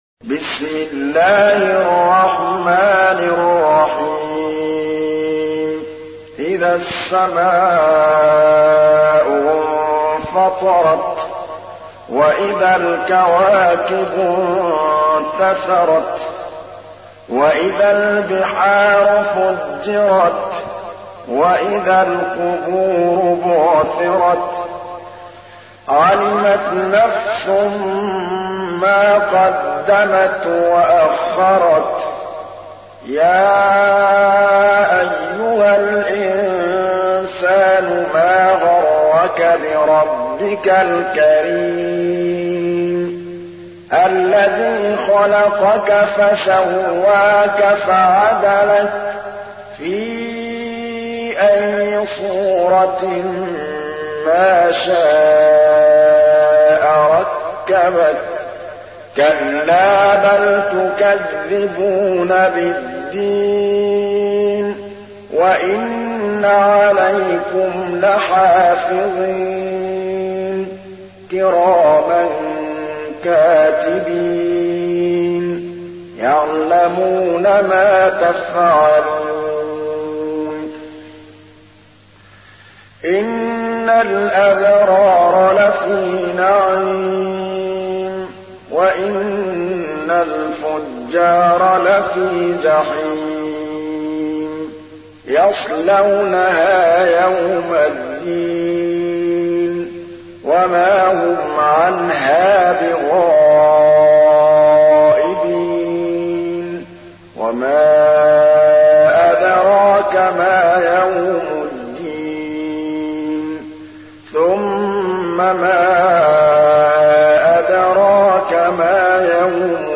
تحميل : 82. سورة الانفطار / القارئ محمود الطبلاوي / القرآن الكريم / موقع يا حسين